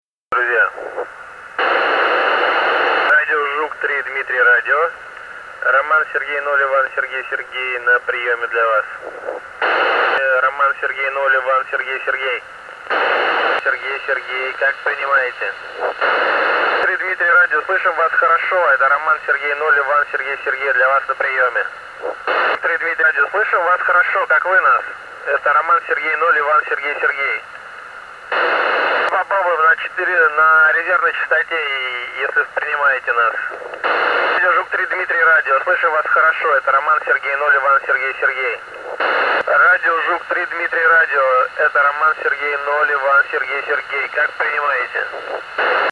RS0ISS VOICE
RS0ISS 24.02.2018, 145,800 MHz, 08:47 UTC
Gets in Russian, very loud clear signal, unfortunately no connection to me.